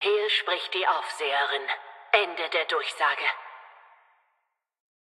Fallout 76: Audiodialoge